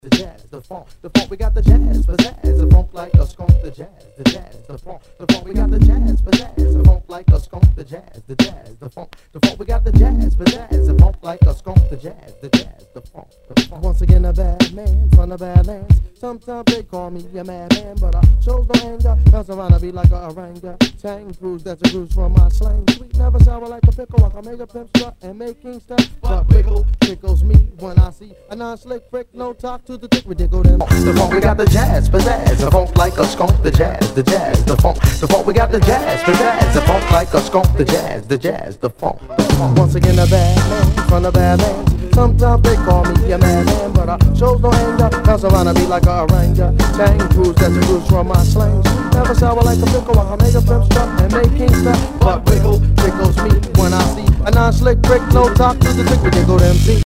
当前位置 > 首页 >音乐 >唱片 >说唱，嘻哈
HIPHOP/R&B